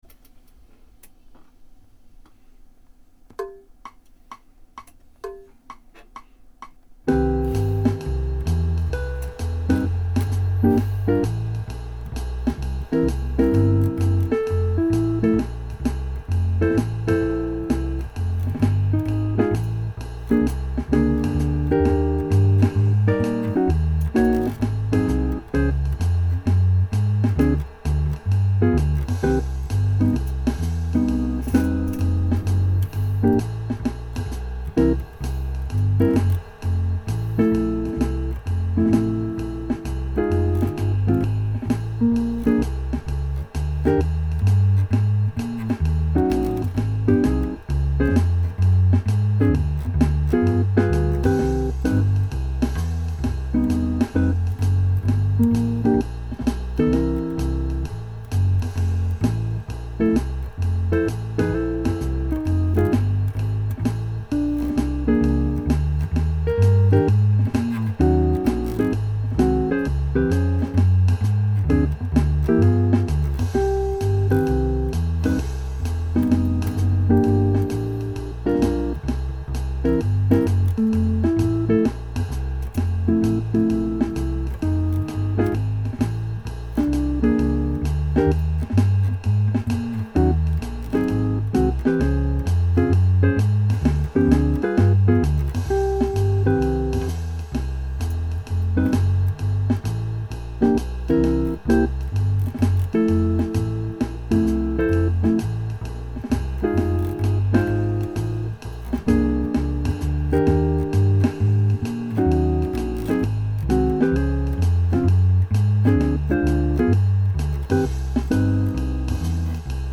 It is just adding my bass to a backing track